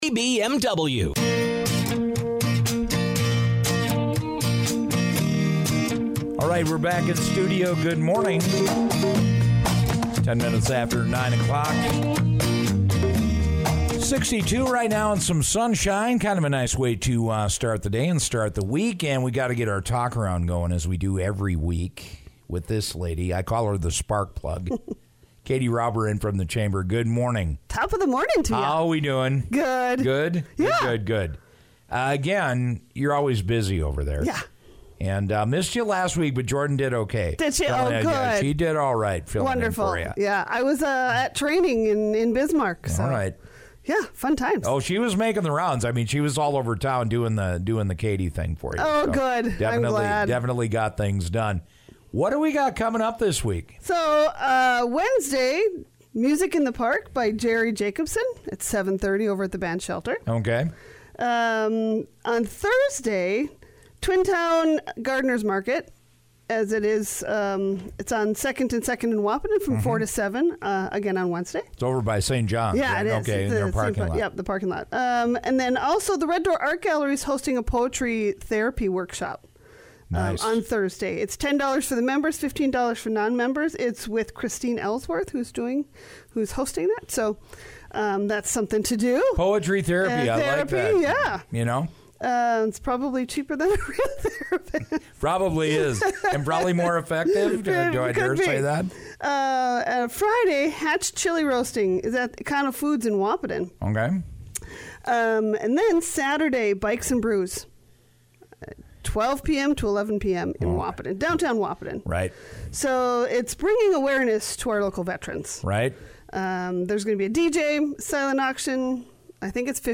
radio segment